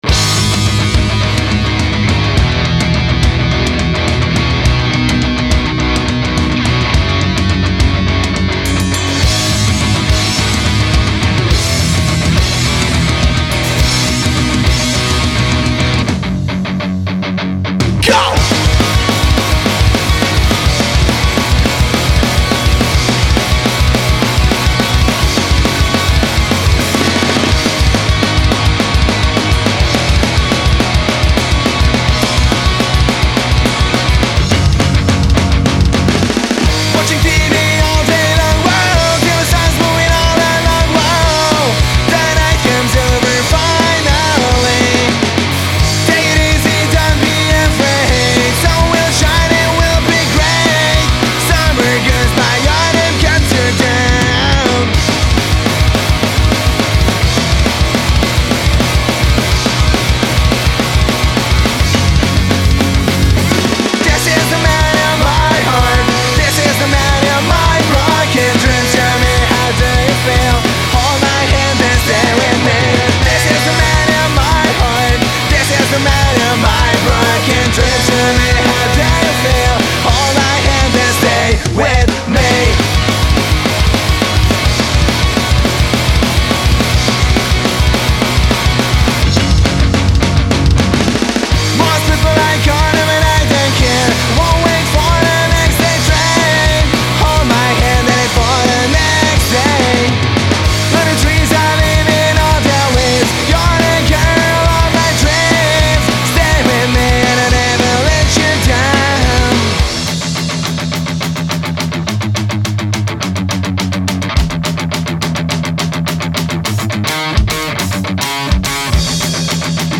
punkrock / melodycore spiced with poppish vocals
Vocals / Guitar
Drums
Bass